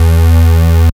72.02 BASS.wav